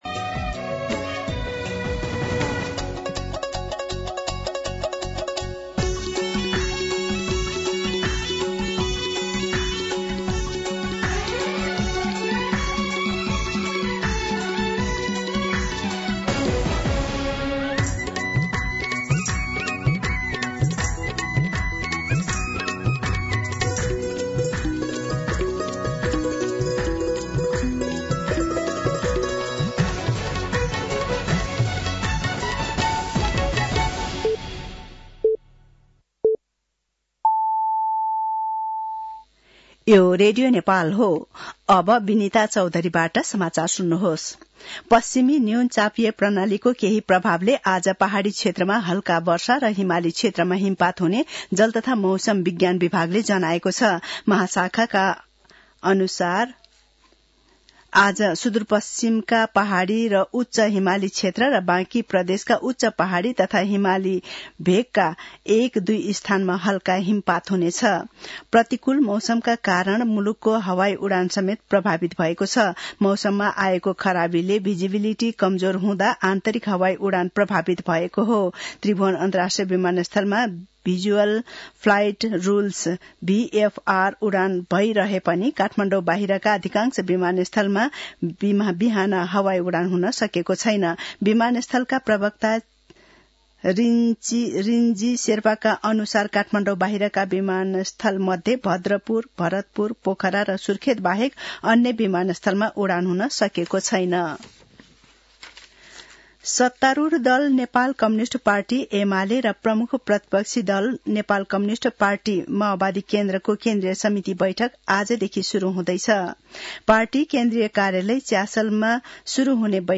मध्यान्ह १२ बजेको नेपाली समाचार : २२ पुष , २०८१